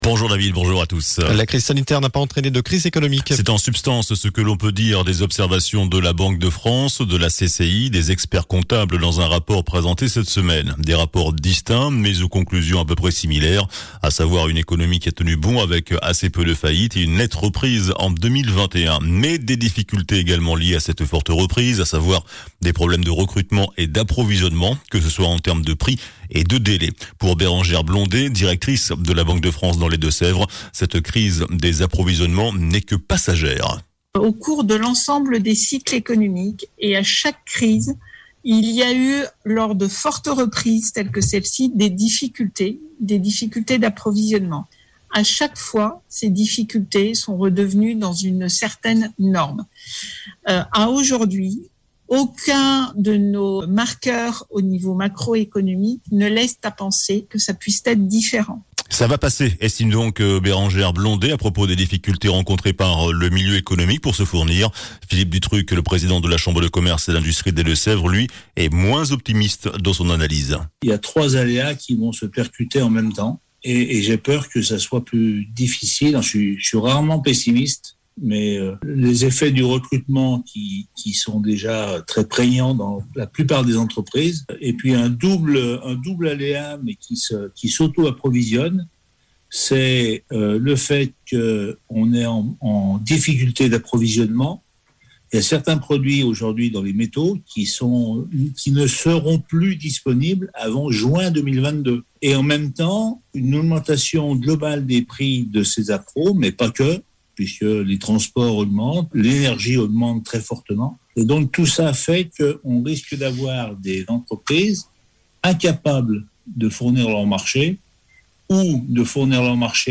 JOURNAL DU SAMEDI 09 OCTOBRE